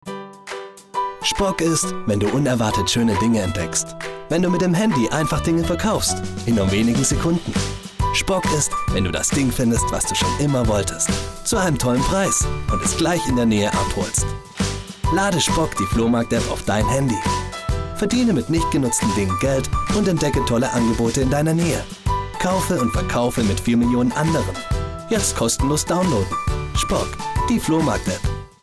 Voiceover & Sprecher
Als Sprecher für Voiceover, Werbung, Imagefilme, Eventvideos, Hörbücher und Hörspiele bringe ich Inhalte authentisch und emotional auf den Punkt – ruhig oder dynamisch, seriös oder lebendig, passend zu deinem Projekt.